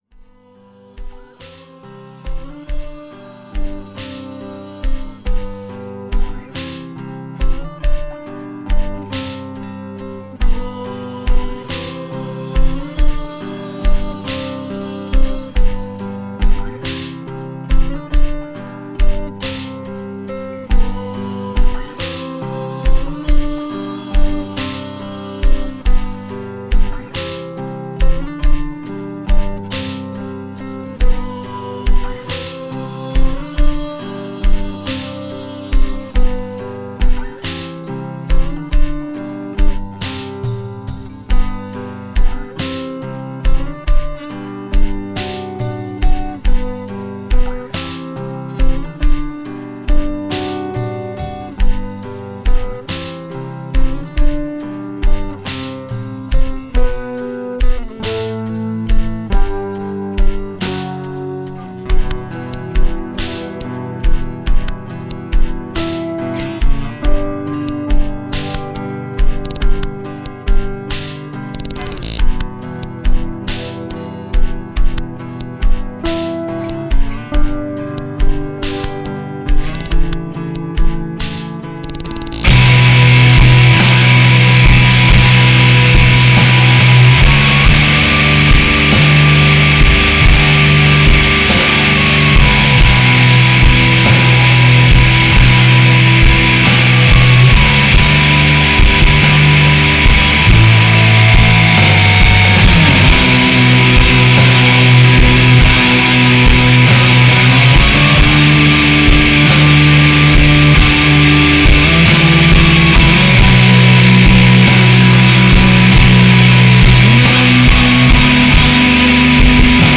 adding occasional prog-like complexity to their heavy punk.